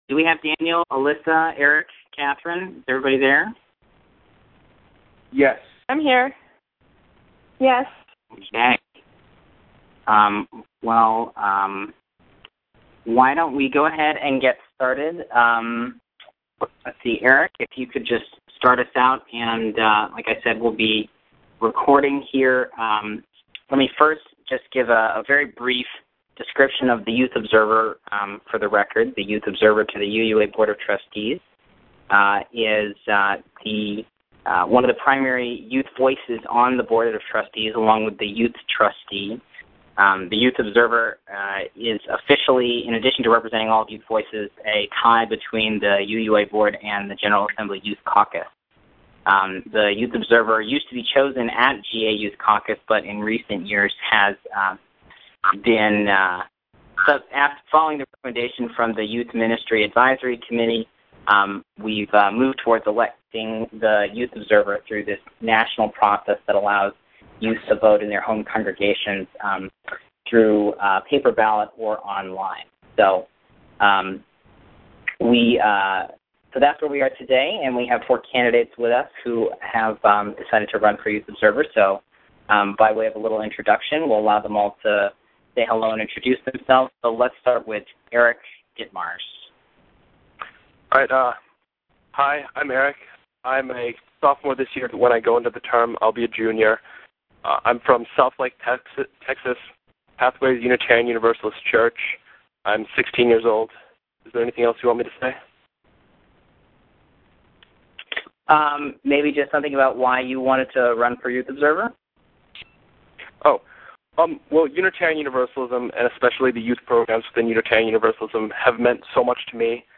Wednesday's Question and Answer Session with Youth Observer Candidates is now available online. Visit the Youth Observer website to listen to the call before your youth group submits its ballot.